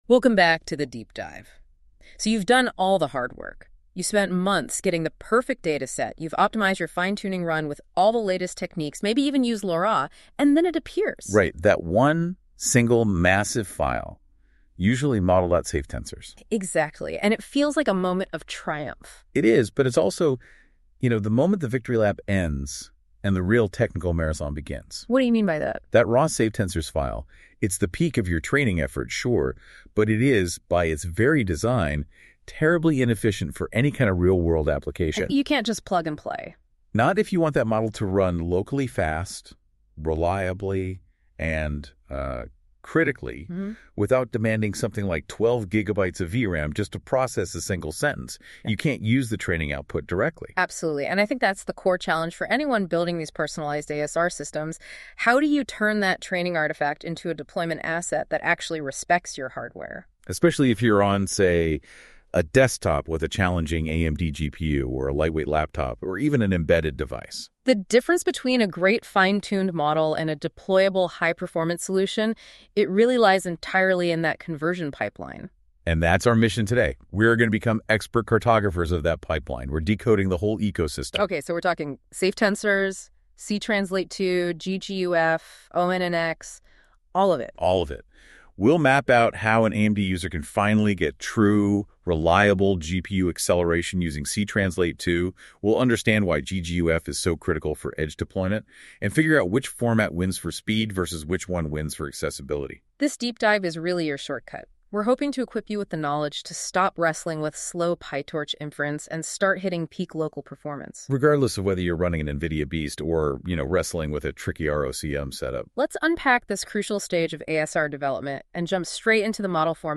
AI-Generated Content: This podcast is created using AI personas.
TTS Engine chatterbox-tts